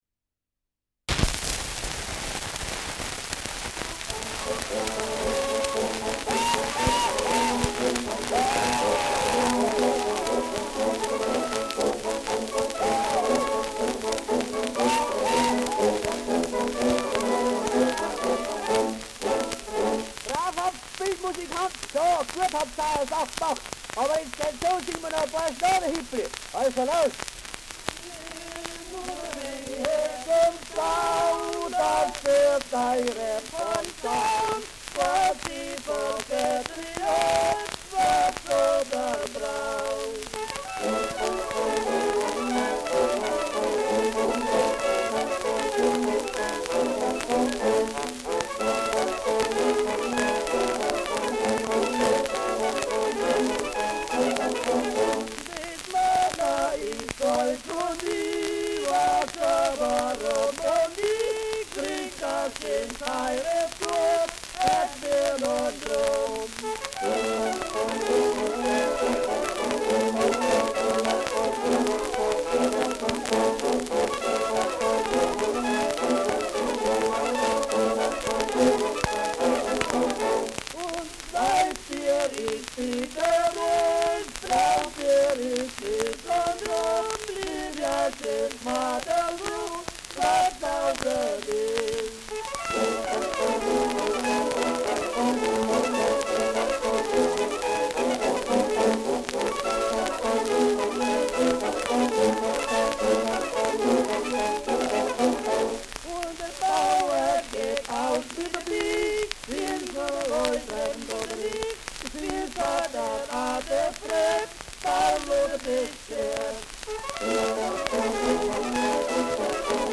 Schellackplatte
Starkes Grundknistern : Durchgehend leichtes bis starkes Knacken
[Ansbach] (Aufnahmeort)